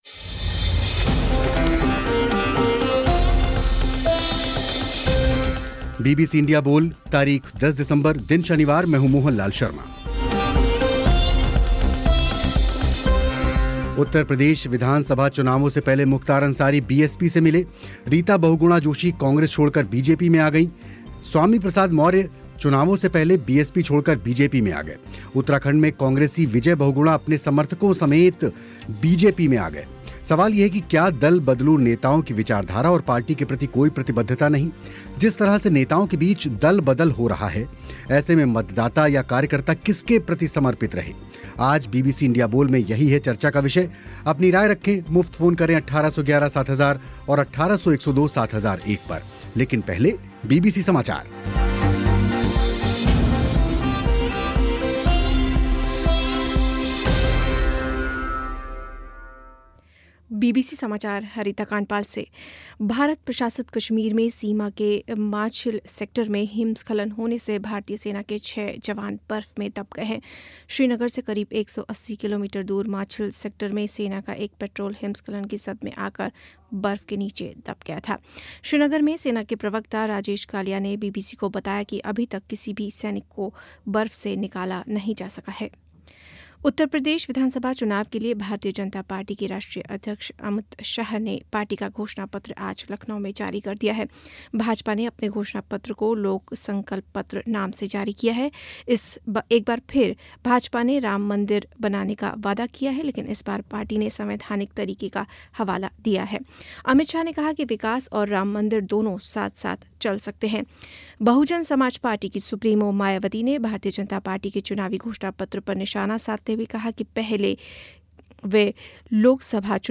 इसी पर हुई चर्चा